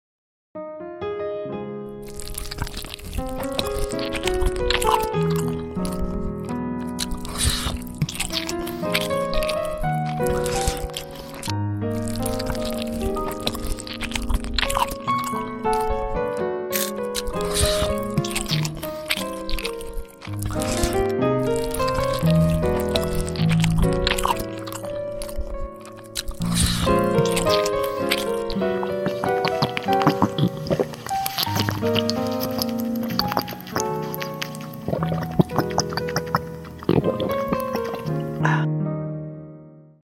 Battery Overcharged By Eating Fast sound effects free download
Battery Overcharged By Eating Fast Food _ Asmr Mukbang Animation _ Battery Charging Animation